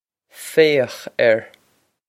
Fay-ochk err
This is an approximate phonetic pronunciation of the phrase.